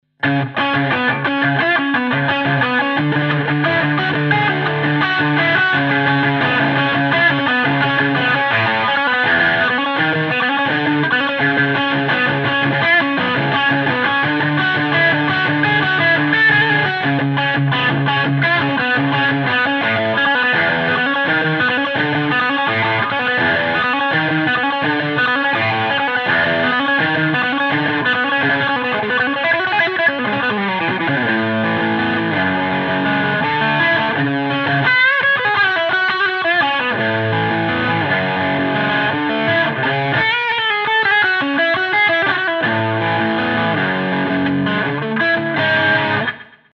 To ensure the amp’s true voice shines through, recordings were made using a Shure SM57 mic on a Marshall cabinet, alongside a direct line from the amp’s balanced SM57 mic sim output.
We kept effects minimal to let the amp speak for itself — just a hint of reverb, light delay, and a short wah pedal section.”
The_Sabre_low_ovd_1_demomp3.m4a